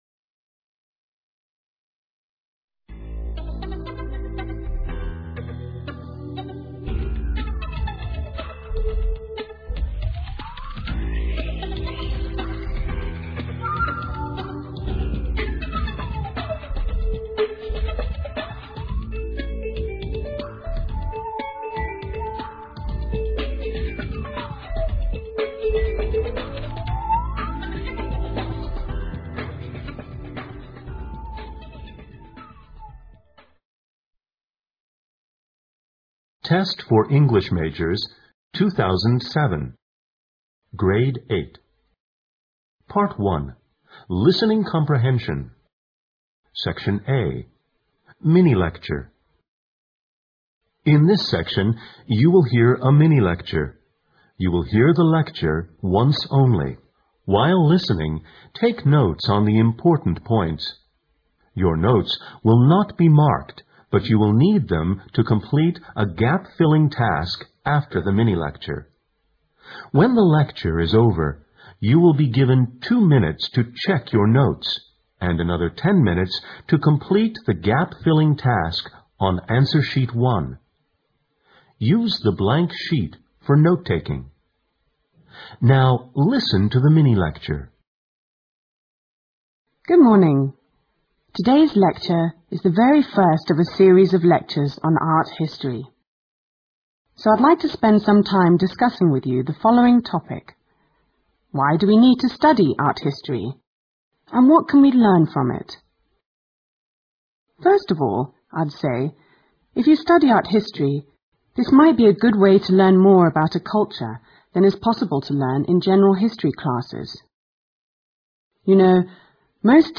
PART I LISTENING COMPREHENSION (35 MIN)SECTION A Mini-lectureIn this section you will hear a mini-lecture. You will hear the lecture once only.